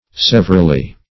Severally \Sev"er*al*ly\, adv.